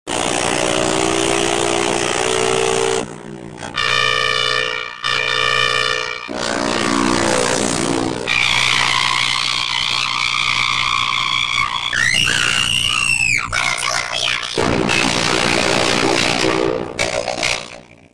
Alien Car Crashing Sound effect
Alien Cartoon High-pitch sound effect free sound royalty free Sound Effects